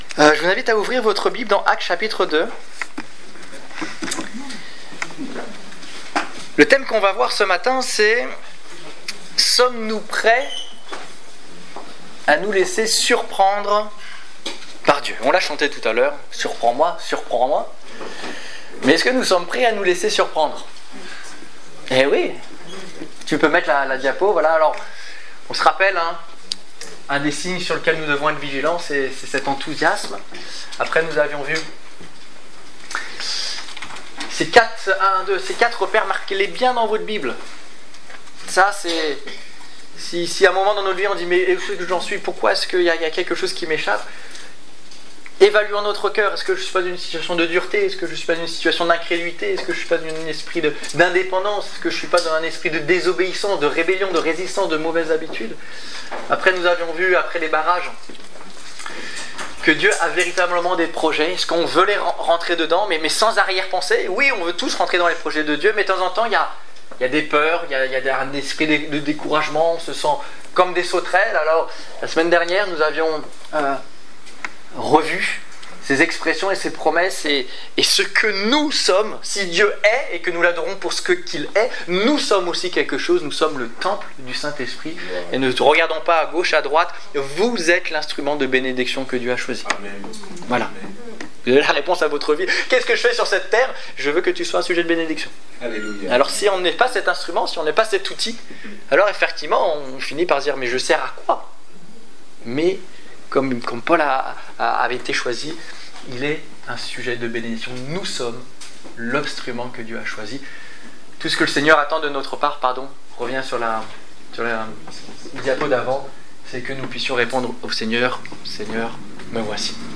Culte du 30 novembre 2014 Ecoutez l'enregistrement de ce message à l'aide du lecteur